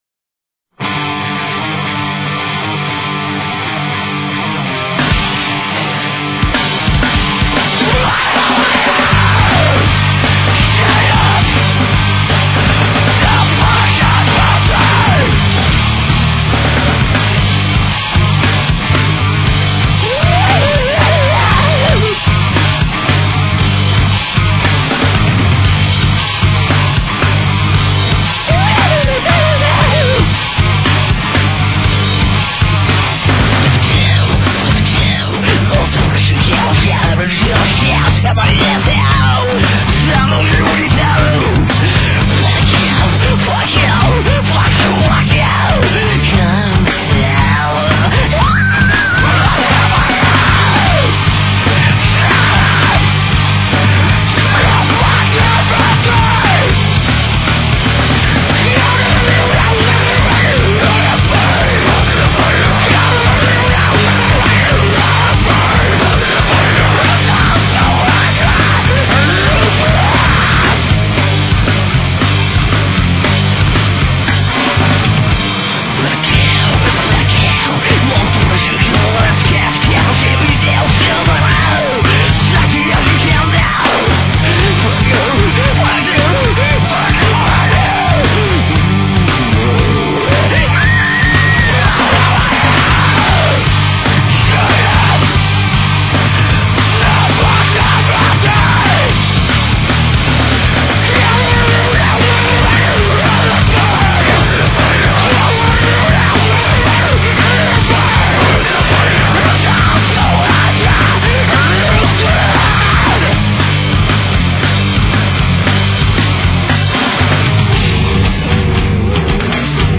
2 songs in bad quality.